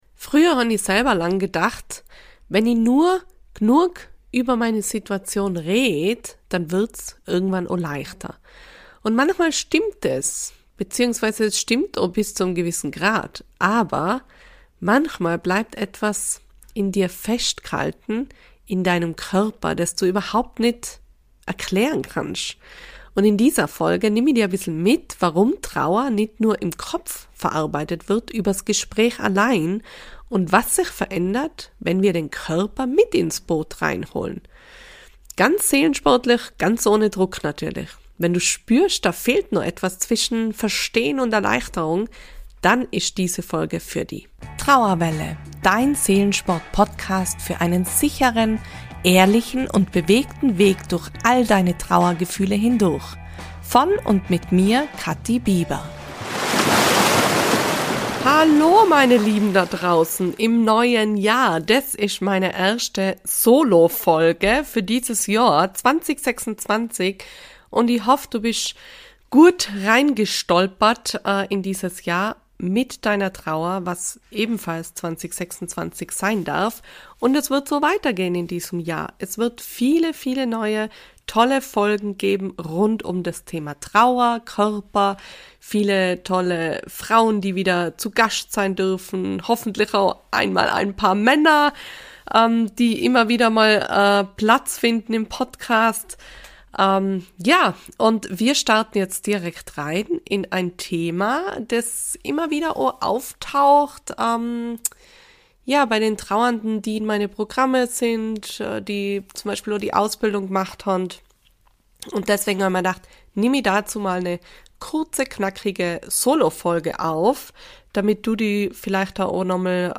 Ich spreche in dieser Solofolge darüber, warum Reden in der Trauer so gut tun kann – und warum sich dein Körper trotzdem noch angespannt, schwer oder wie „im Alarm“ anfühlen kann.